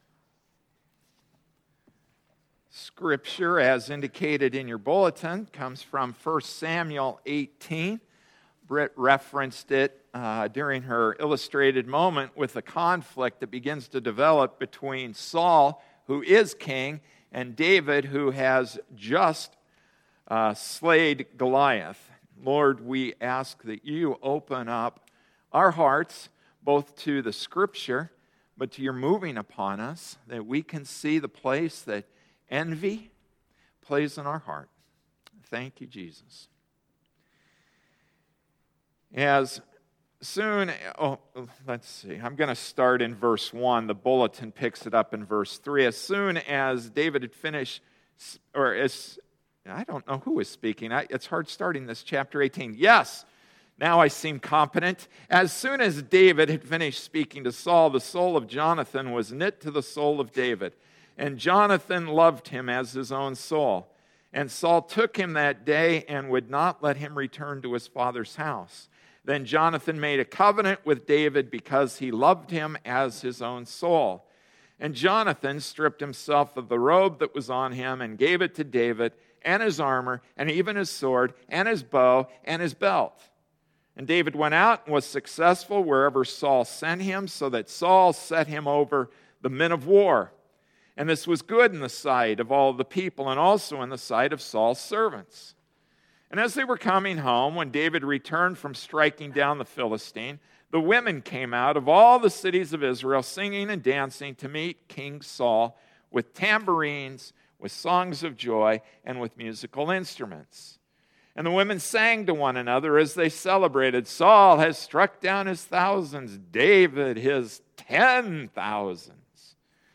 June 15, 2014 Envy to Goodness Passage: 1 Samuel 18:1-16 Service Type: Sunday Morning Service “Envy to Goodness” Introduction: Envy [jealousy, covetousness] wants what another has (i.e. success, health, stuff, attention).